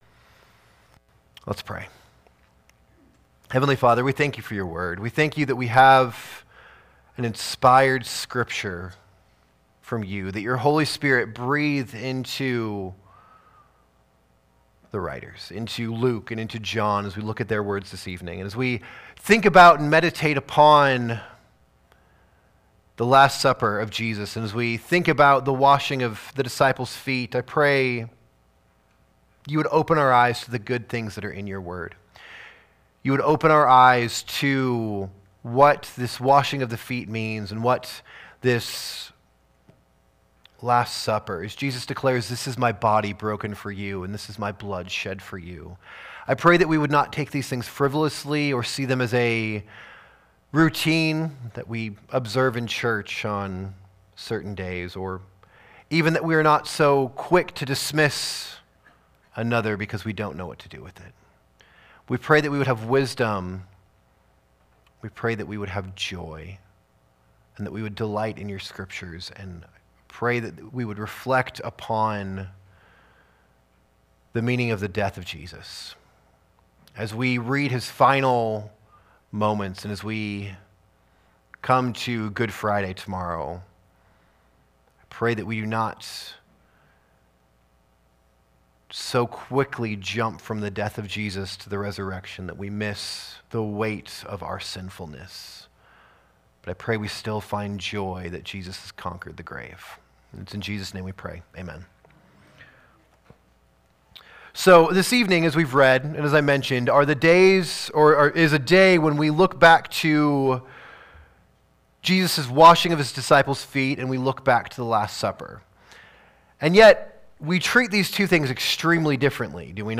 Sermons | Maranatha Baptist Church
From our 2024 Maundy Thursday service.